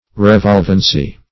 Search Result for " revolvency" : The Collaborative International Dictionary of English v.0.48: Revolvency \Re*volv"en*cy\, n. The act or state of revolving; revolution.